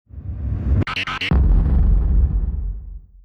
Royalty-free sound effects and music beds that came with iMovie and the iLife suite back in the day for use in videos.
Booming Rumble.m4a